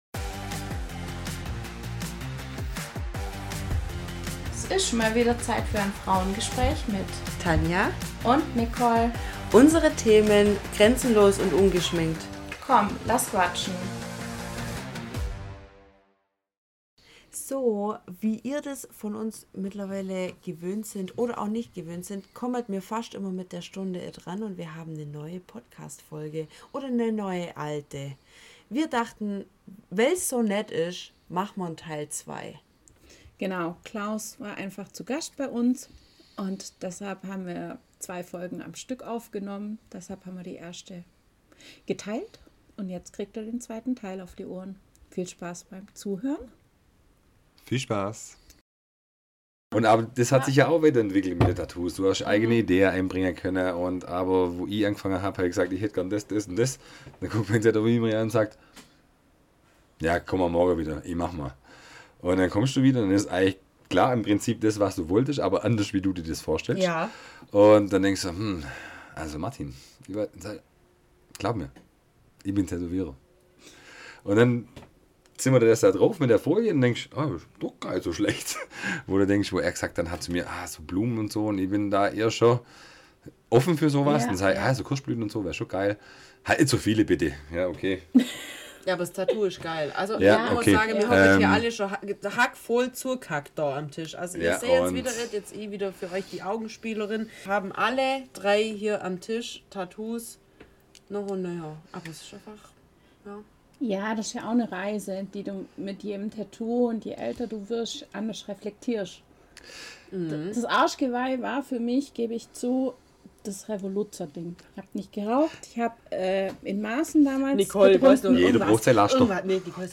Diese Folge der nahtlose Übergang von Folge 21, da wir dies in einem Stück aufgenommen hatten.